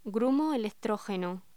Locución: Grumo electrógeno